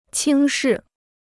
轻视 (qīng shì) Free Chinese Dictionary